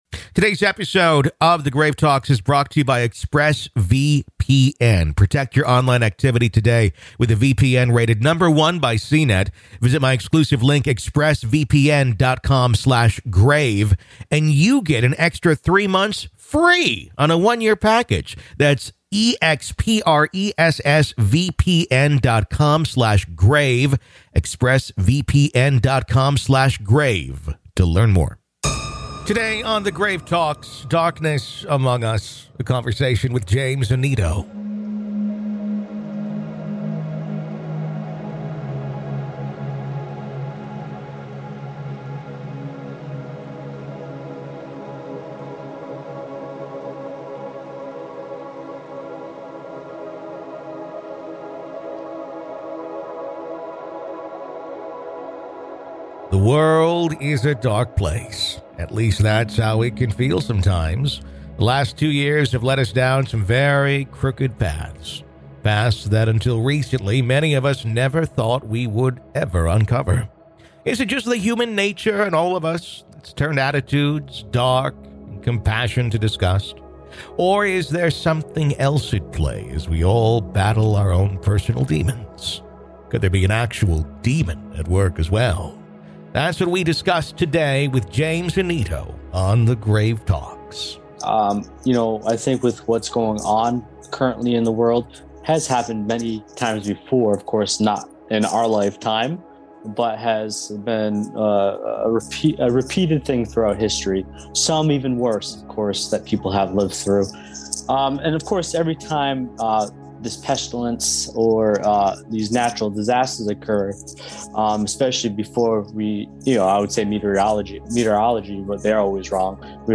Darkness Among Us | A Conversation